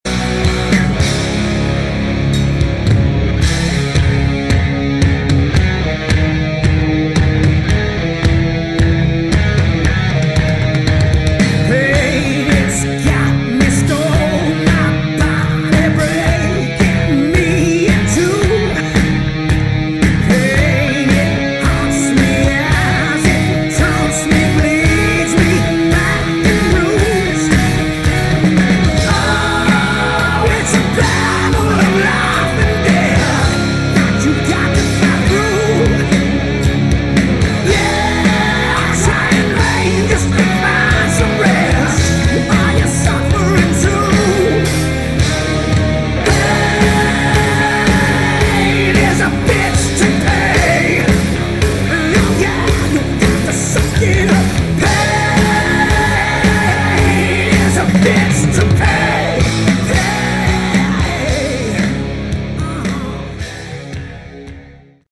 Category: Hard Rock
vocals, keyboard, guitar
lead guitar, backing vocals